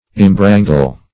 Search Result for " imbrangle" : The Collaborative International Dictionary of English v.0.48: Imbrangle \Im*bran"gle\, v. t. To entangle as in a cobweb; to mix confusedly.